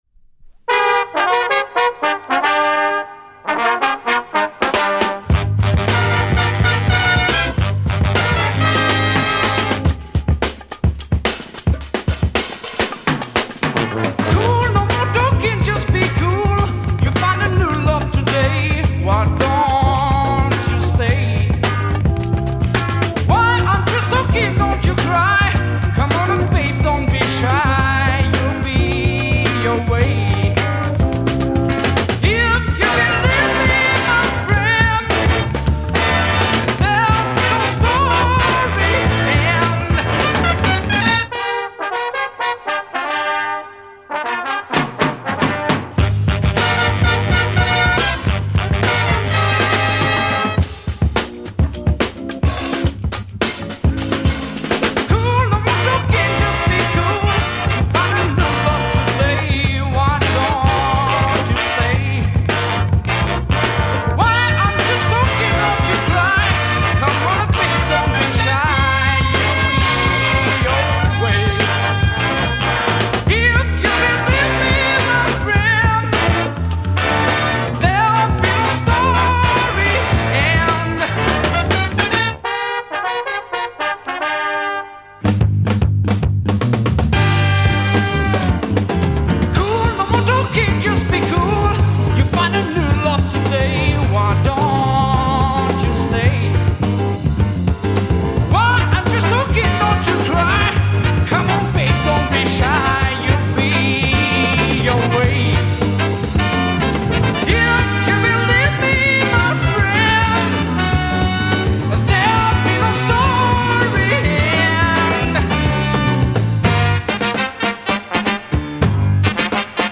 Female French soul mod